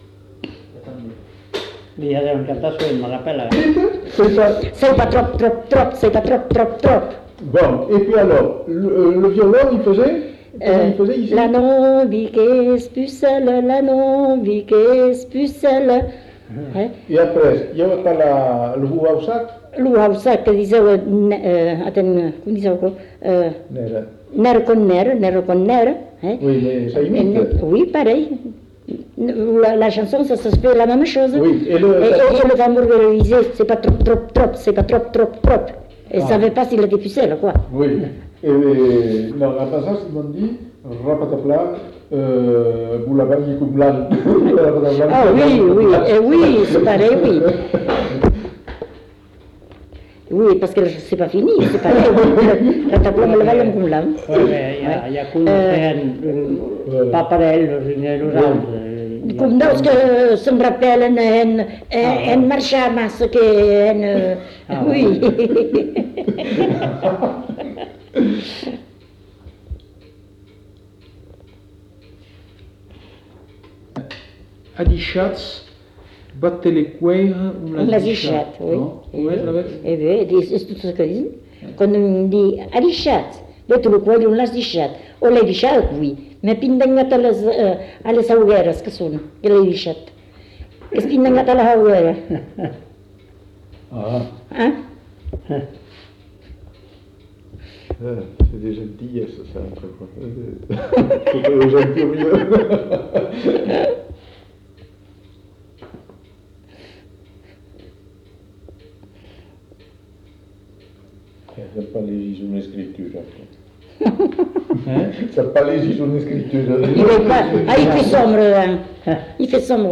Lieu : Cazalis
Genre : chant
Effectif : 1
Type de voix : voix de femme
Production du son : chanté ; parlé